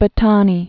(bə-tänē), al- 858?-929.